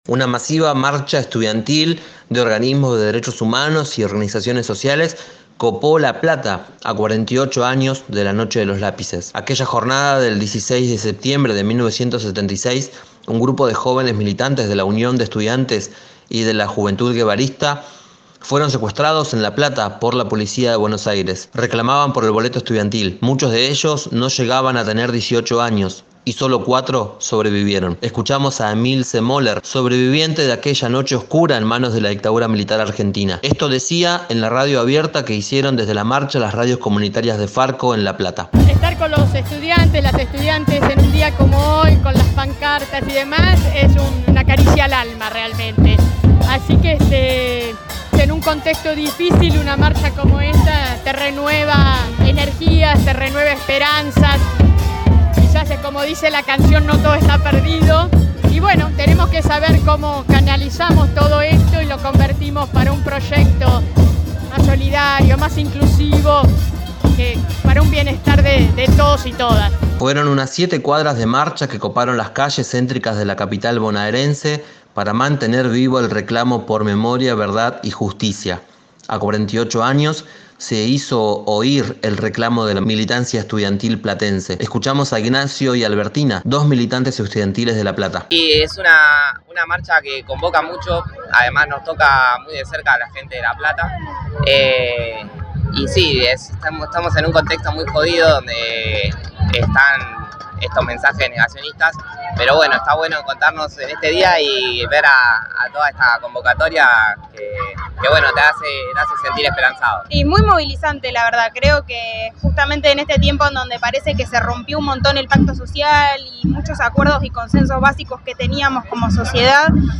Las radios de FARCO en la ciudad realizaron una transmisión especial desde la movilización.